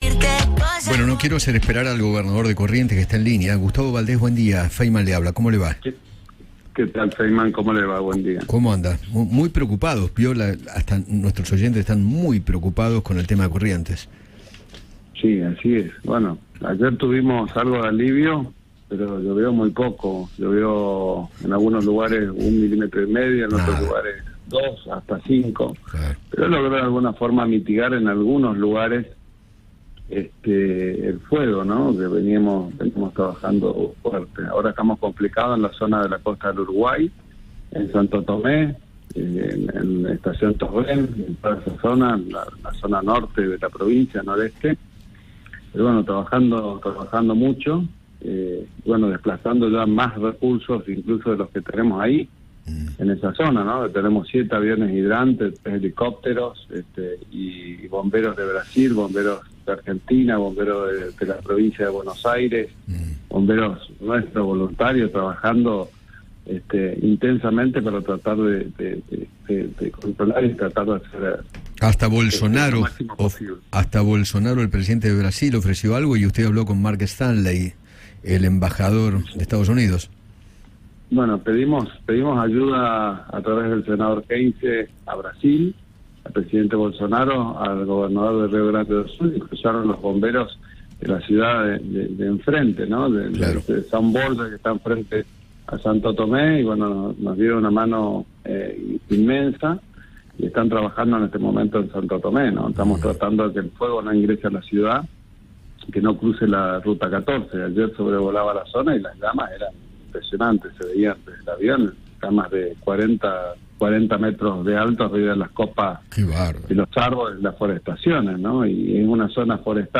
Gustavo Valdés, gobernador de Corrientes, conversó con Eduardo Feinmann sobre los graves incendios, la ayuda recibida por parte de sus colegas, y confirmó el reclamo que le realizó el ministro Juan Cabandié.